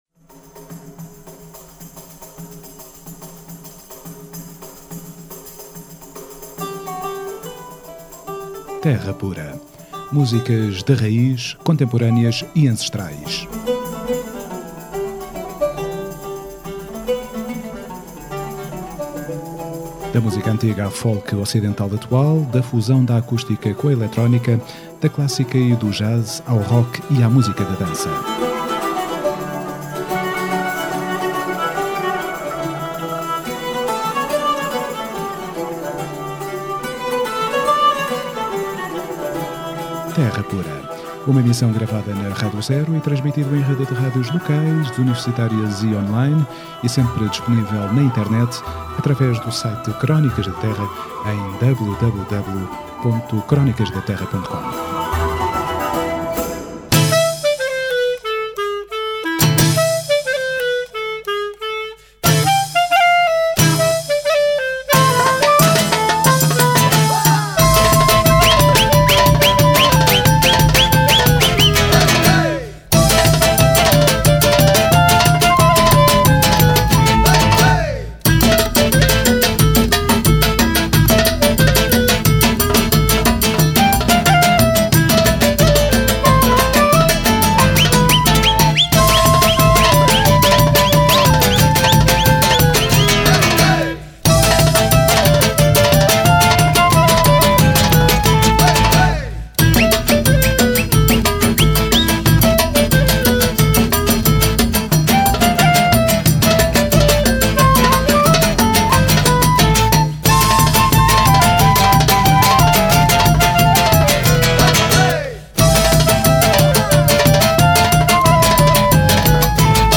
Em Karrossel há música tradicional portuguesa e europeia em espírito de festa, como viras, malhões, fado batido e danças bretãs, romenas, lituanas, gregas, tocado e dançado em interacção com o público.
Entrevista Karrossel